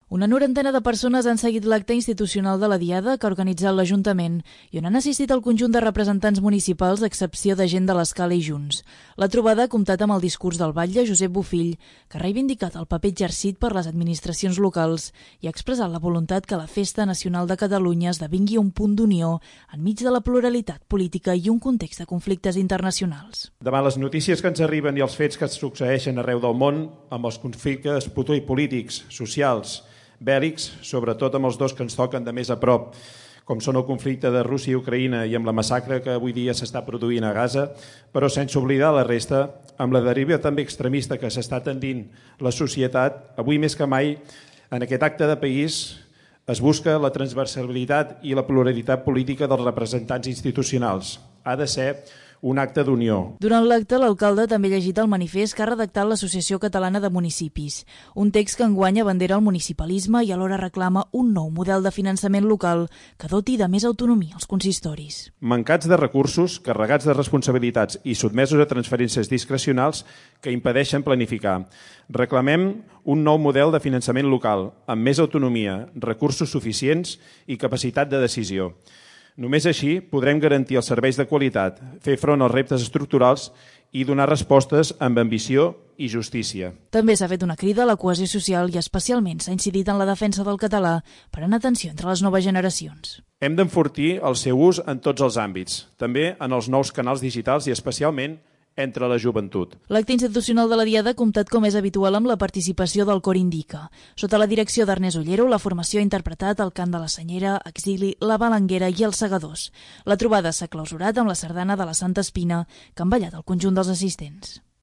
L'acte institucional de la Diada ha congregat una norantena de persones a la plaça de l'Ajuntament. El batlle ha llegit el manifest de l'Associació Catalana de Municipis, i ha fet una crida a la 'unió' en un context de polarització i conflictes internacionals. La trobada ha finalitzat amb un recital del Cor Indika, i la ballada de la Santa Espina.